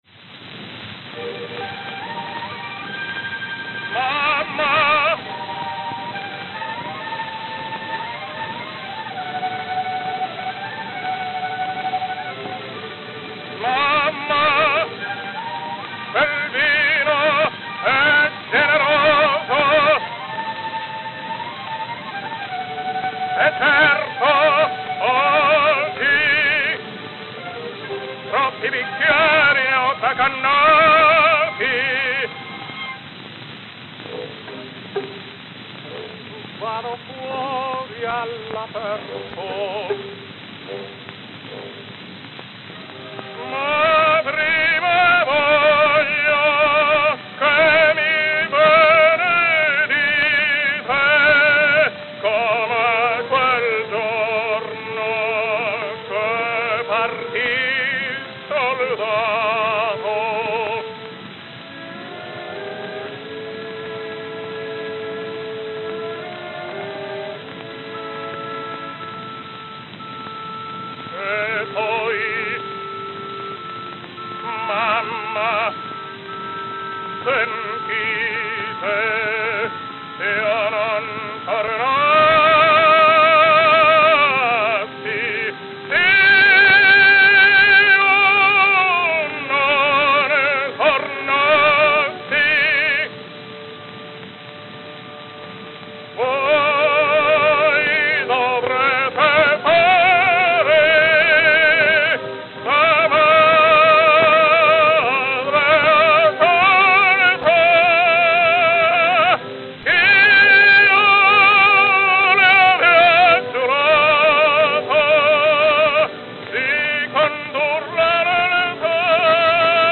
12-Inch Records
Note: played at 75 RPM